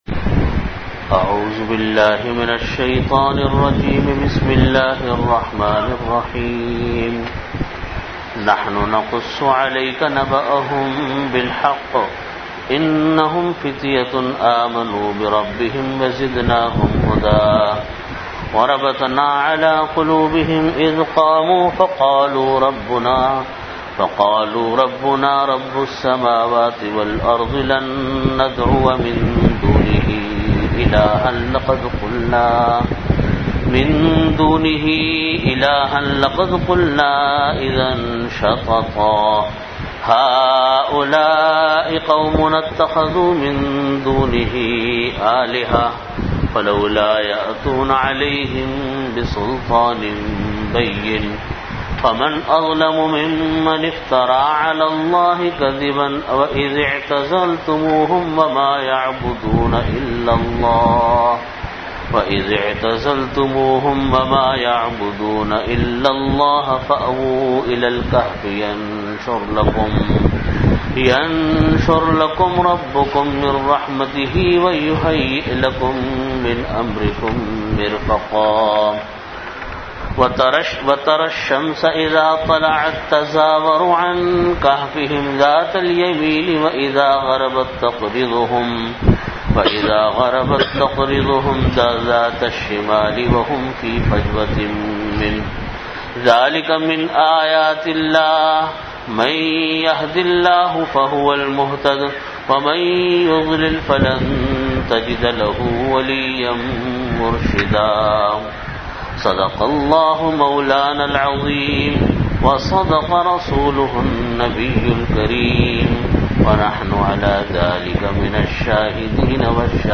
Audio Category: Bayanat
Venue: Jamia Masjid Bait-ul-Mukkaram, Karachi